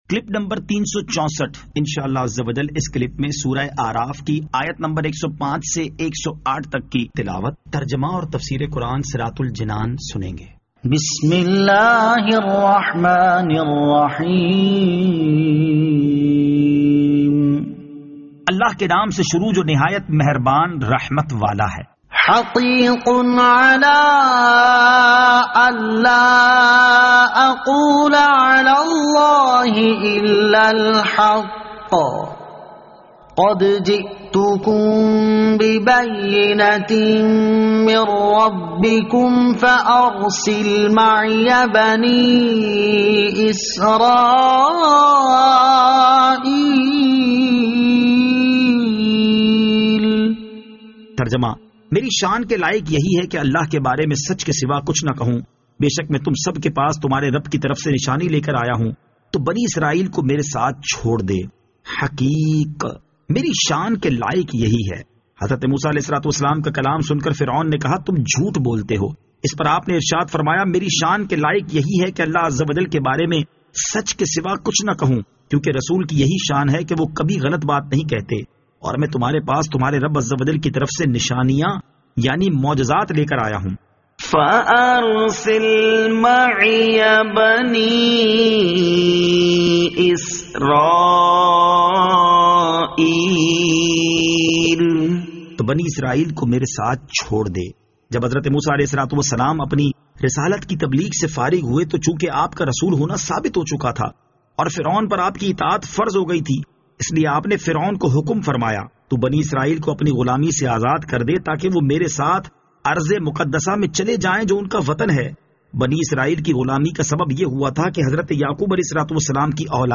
Surah Al-A'raf Ayat 105 To 108 Tilawat , Tarjama , Tafseer